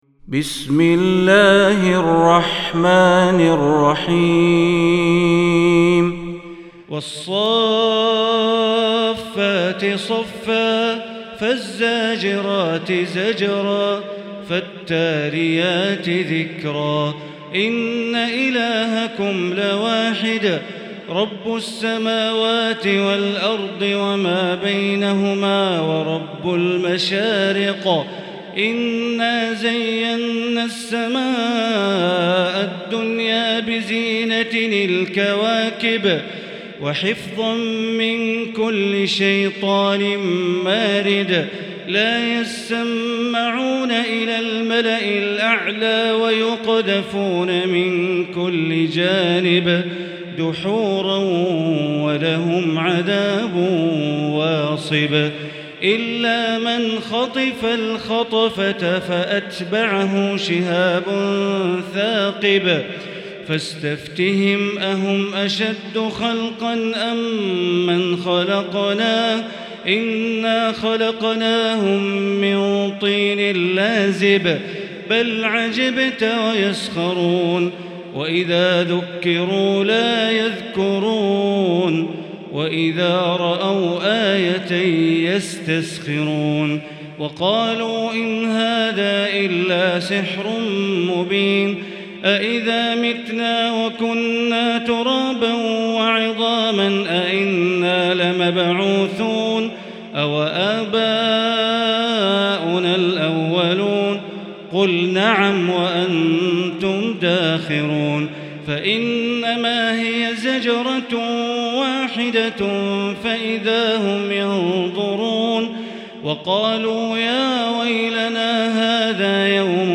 المكان: المسجد الحرام الشيخ: معالي الشيخ أ.د. بندر بليلة معالي الشيخ أ.د. بندر بليلة فضيلة الشيخ ياسر الدوسري الصافات